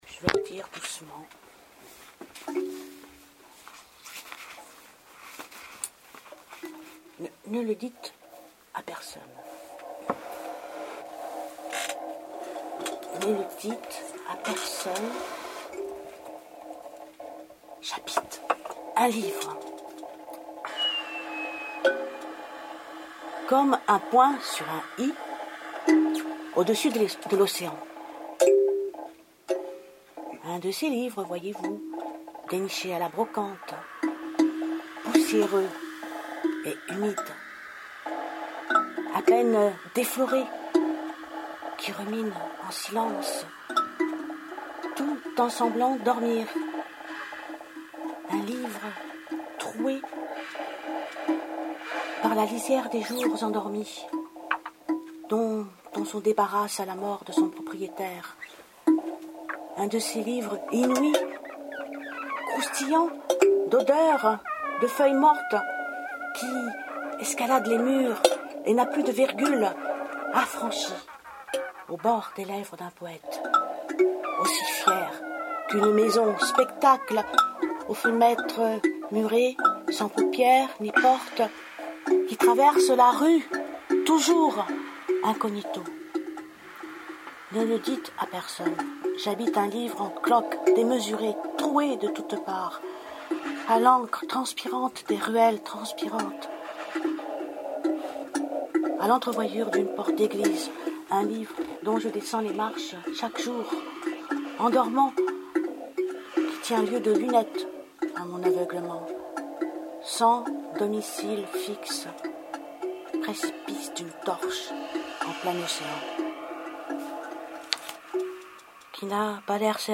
NE LE DITES A PERSONNE Improvisation musicale
(Emission DEUX SOUS DE SCENE Radio libertaire du 6  Octobre 2012)